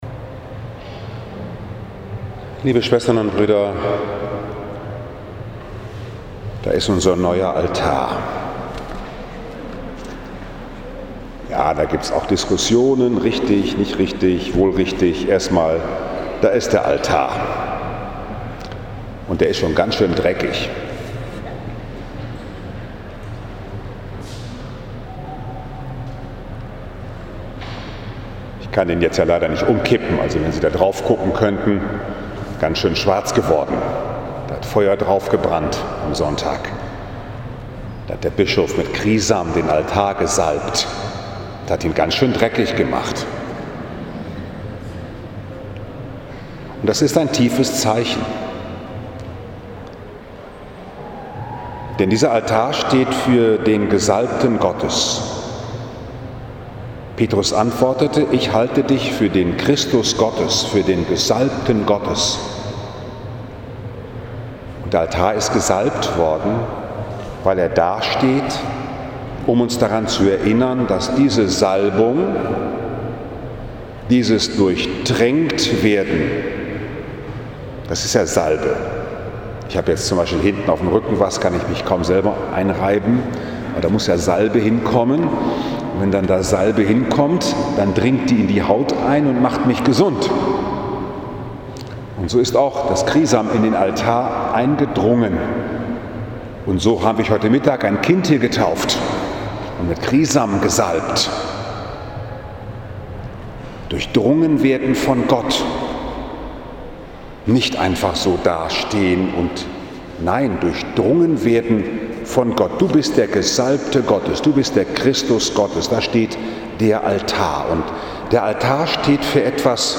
22. Juni 2019, 17 Uhr, Liebfrauenkirche Frankfurt am Main, 12. So. i. J. C
Predigt in Gegenwart eines Ehepaares mit Diamantenem Ehejubiläum